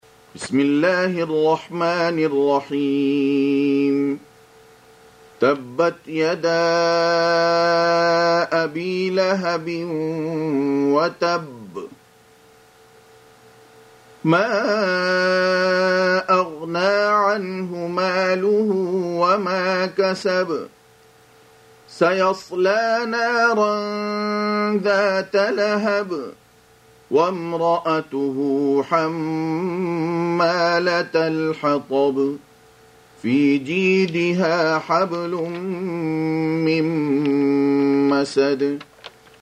Surah Repeating تكرار السورة Download Surah حمّل السورة Reciting Murattalah Audio for 111. Surah Al-Masad سورة المسد N.B *Surah Includes Al-Basmalah Reciters Sequents تتابع التلاوات Reciters Repeats تكرار التلاوات